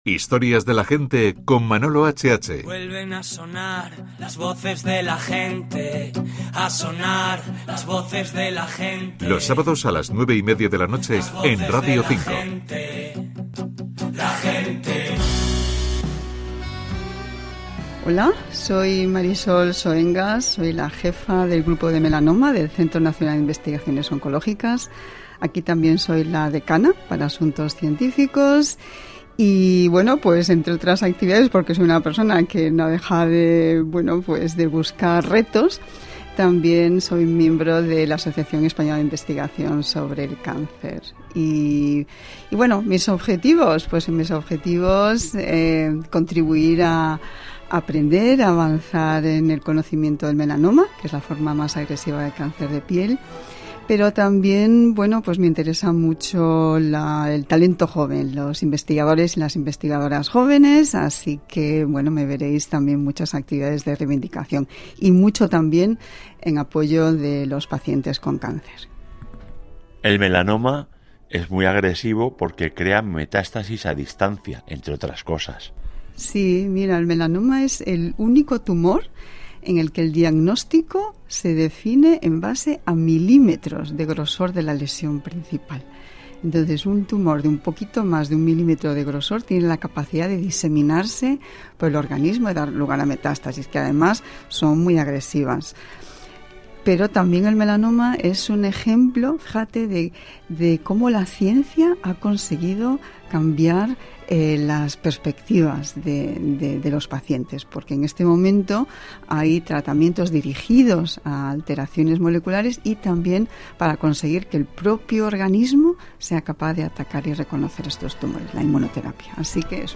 Careta del programa, entrevista